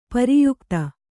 ♪ pari yukta